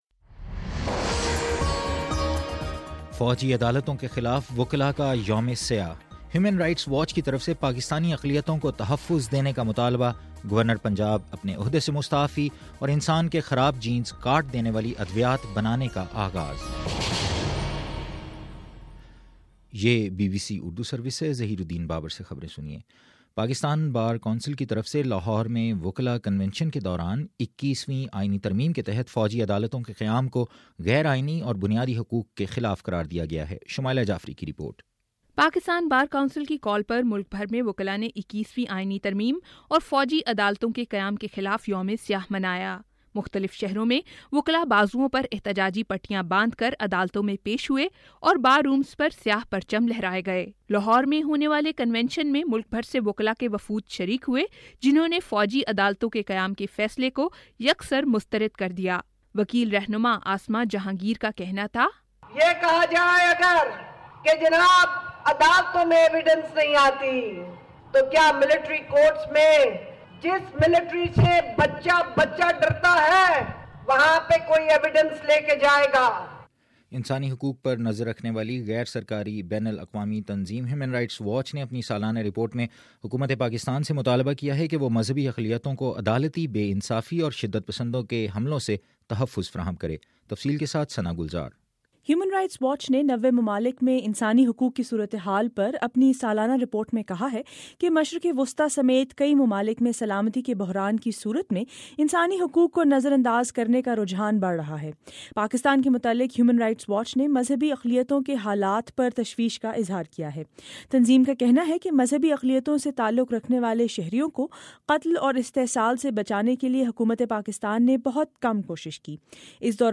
جنوری 29: شام سات بجے کا نیوز بُلیٹن